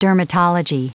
Prononciation du mot : dermatology